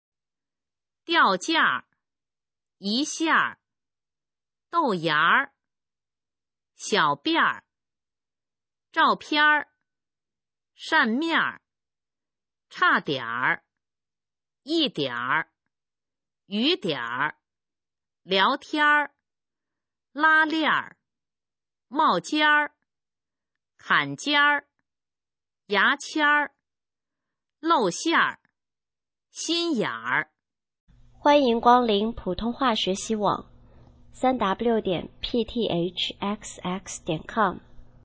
普通话水平测试用儿化词语表示范读音第3部分